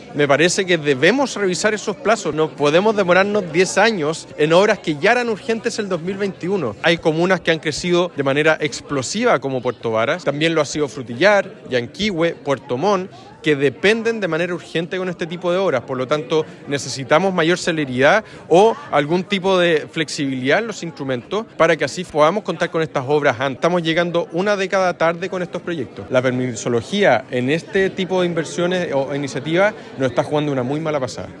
El alcalde de Puerto Varas, Tomás Gárate, dijo que se deben revisar los plazos porque “no podemos demorarnos 10 años en horas que ya eran urgentes en 2021. Hay comunas que han crecido de manera explosiva y que dependen de este tipo de obras”.
alcalde-puerto-varas.mp3